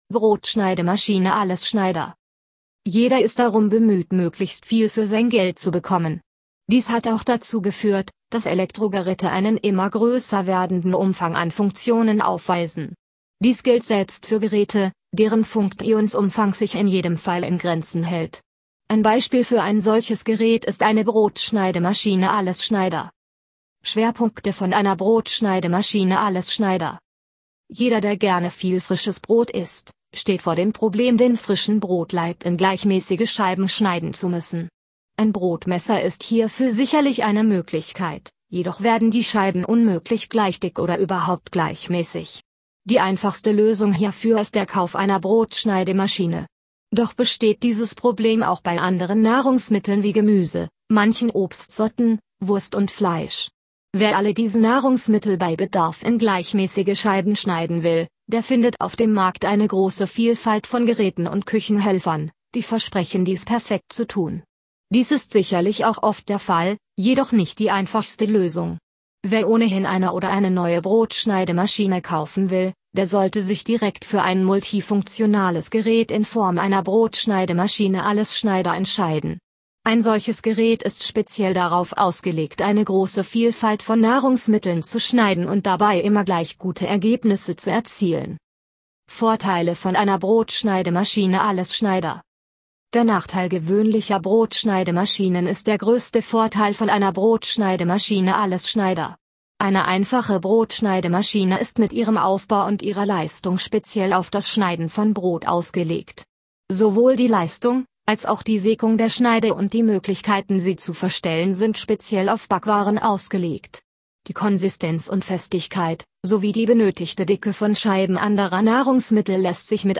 (Audio für Menschen mit Seh- oder Leseschwäche – Wir lesen Ihnen unseren Inhalt vor!)